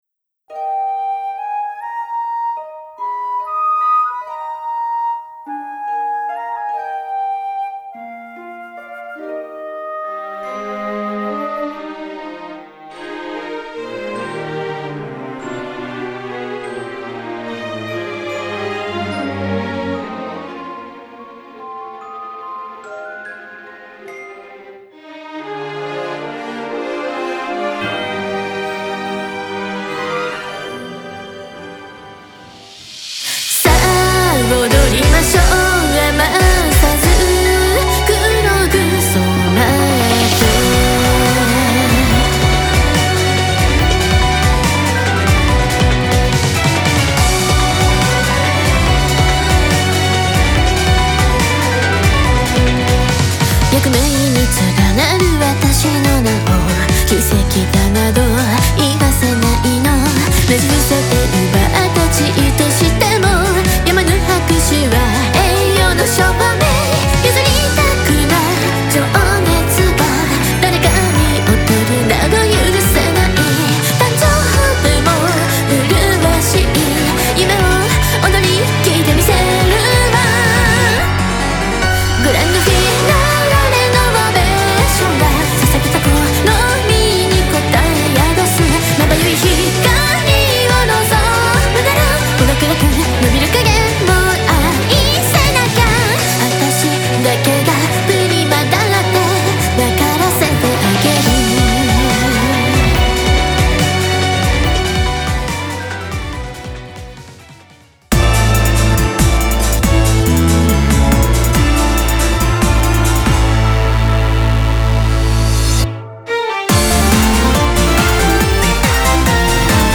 黒ダークメルヘン音楽集
Guitar